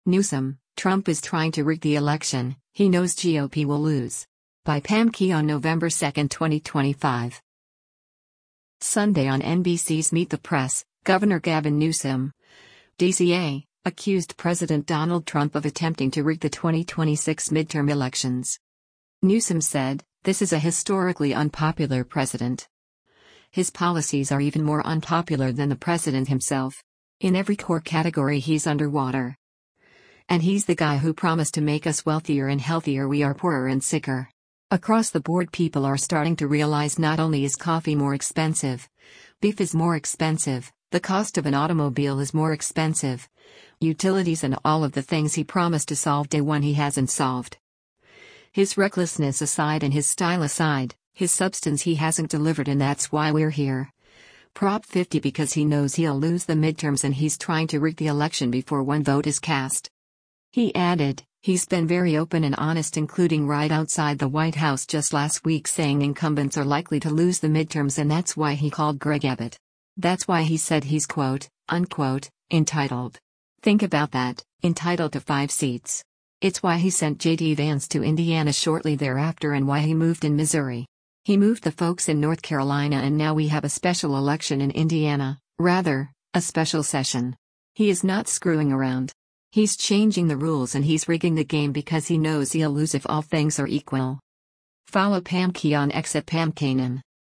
Sunday on NBC’s “Meet the Press,” Gov. Gavin Newsom (D-CA) accused President Donald Trump of attempting to “rig” the 2026 midterm elections.